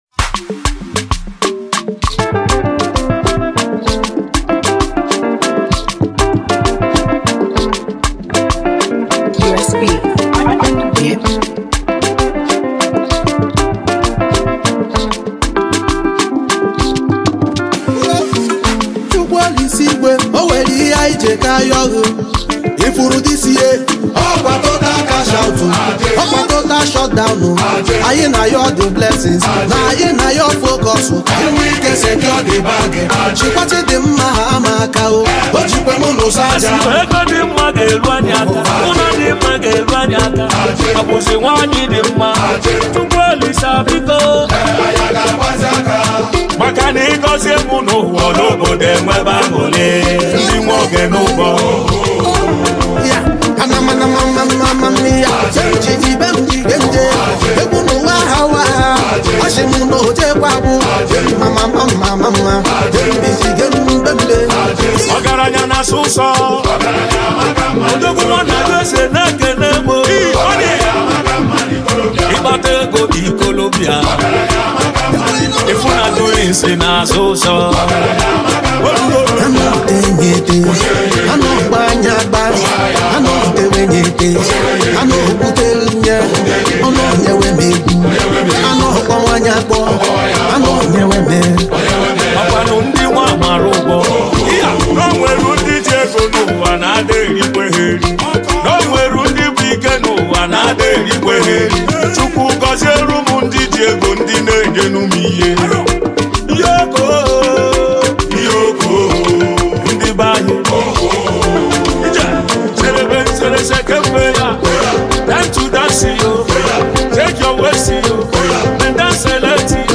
and exceptional vocal delivery for devoted listeners.